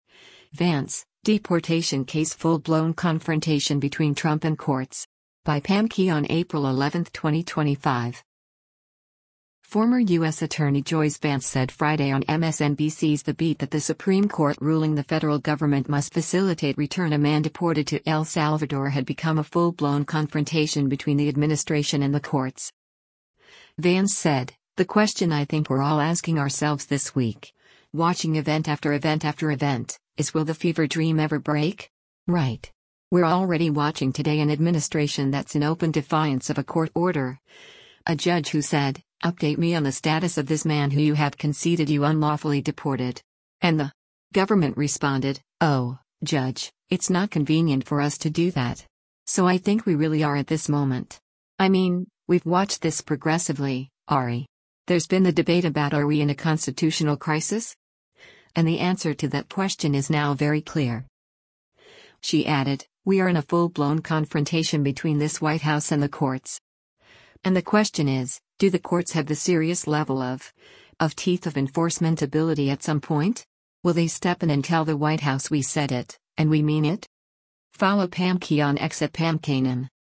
Former U.S. Attorney Joyce Vance said Friday on MSNBC’s “The Beat” that the Supreme Court ruling the federal government must facilitate return a man deported to El Salvador had become a “full-blown confrontation” between the administration and the courts.